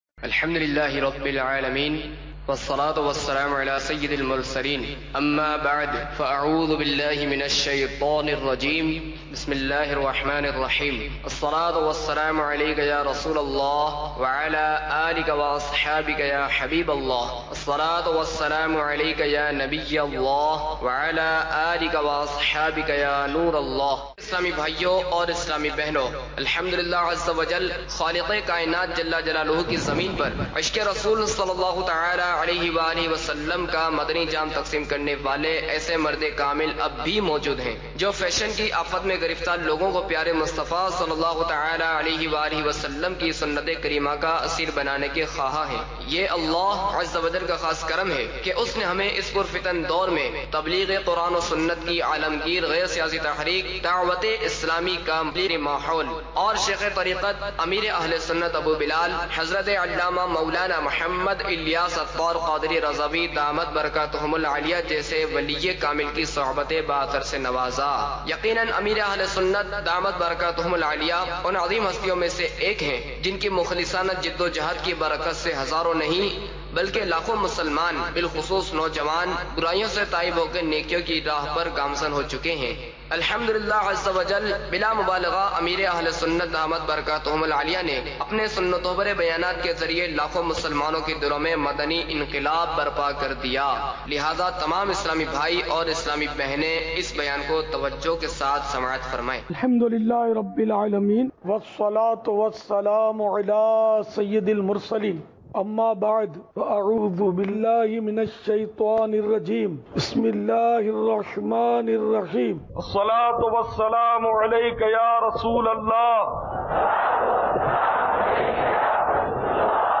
Audio Bayan – Naseehat Ki Baatein